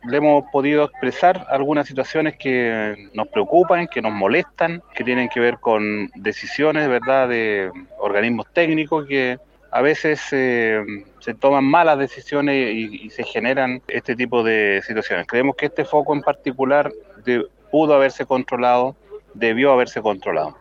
Nibaldo Alegría Alegría, alcalde de Lonquimay, también ha estado recorriendo el lugar y tras el Cogrid, que se realizó en la sede del sector Ránquil, se mostró molesto. Dijo que esta emergencia se debió controlar antes.